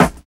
80 SNARE.wav